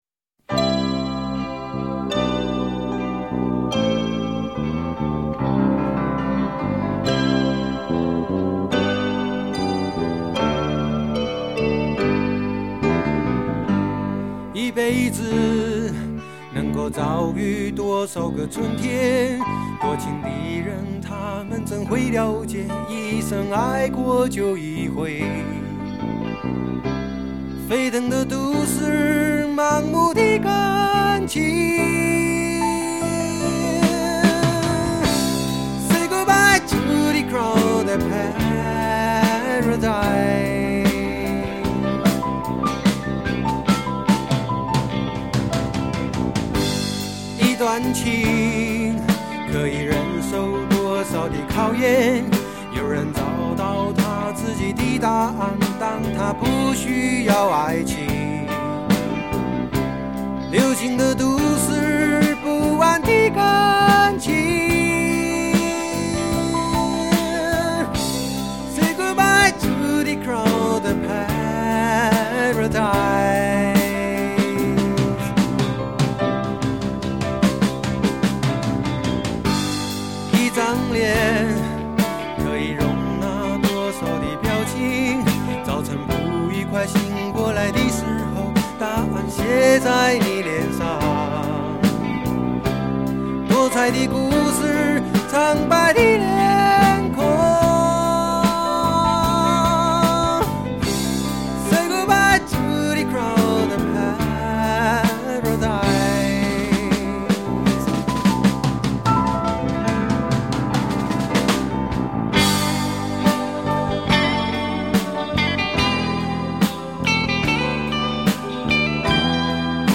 他自己一个人包办了专辑的制作，一半的歌词谱曲都是他亲自完成，吉他、口琴、键盘音色都带有他的个人特色。